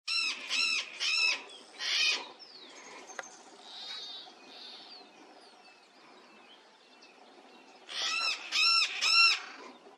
Blue-crowned Parakeet (Thectocercus acuticaudatus)
Life Stage: Adult
Location or protected area: Cercanias de la Reserva Provincial Parque Luro
Condition: Wild
Certainty: Recorded vocal
098-calancate-cabeza-azul.mp3